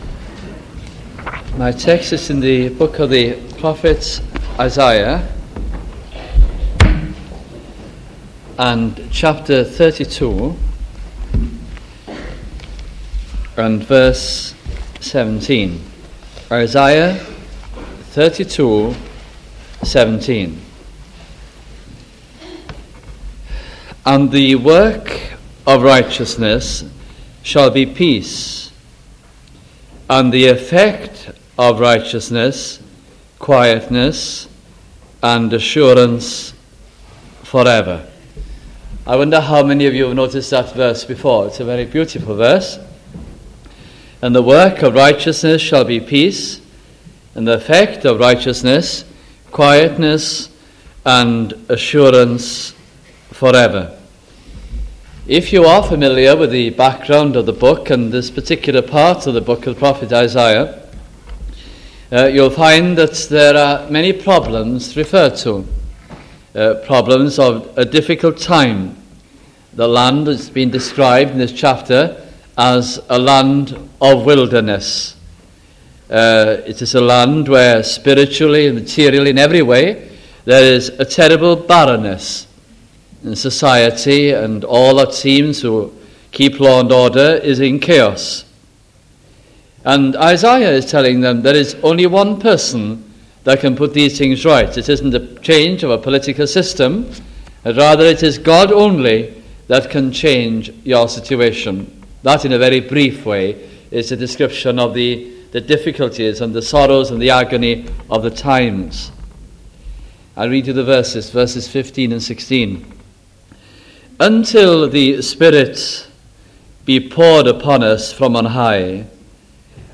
» Isaiah Gospel Sermons